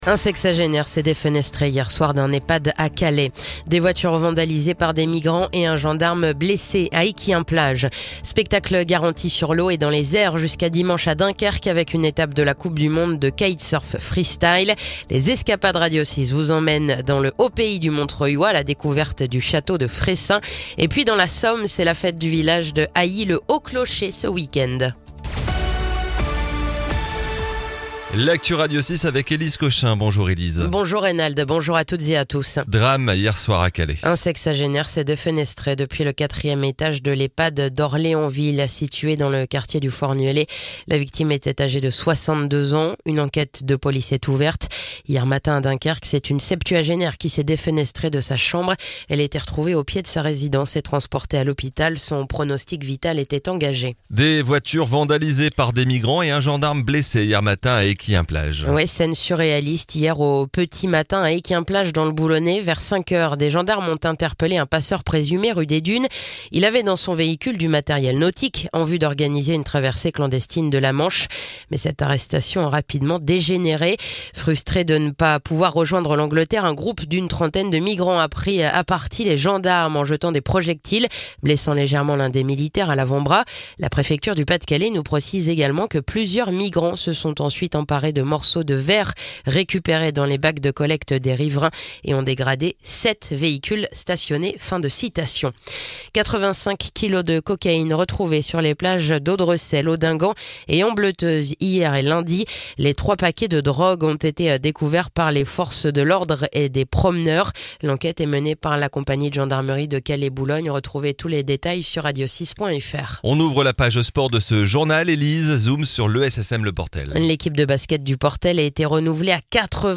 Le journal du mercredi 28 août
(journal de 9h)